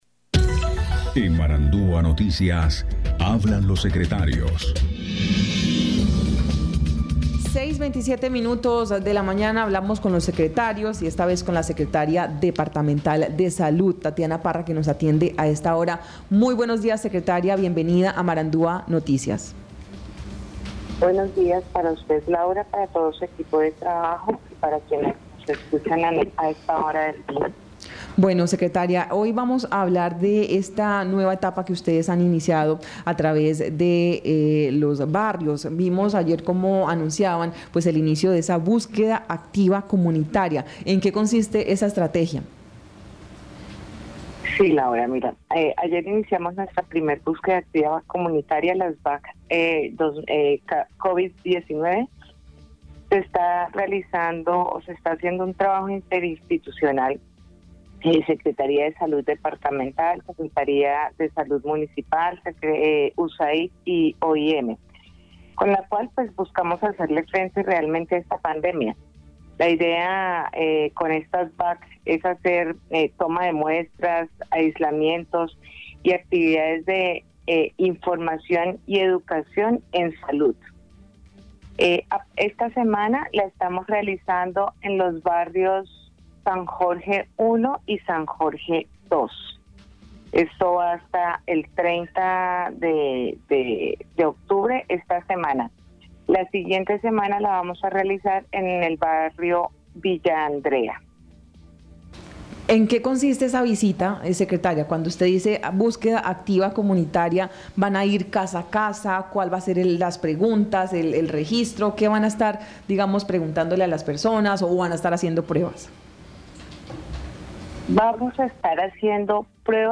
Escuche a Tatiana Parra, secretaria de Salud del Guaviare.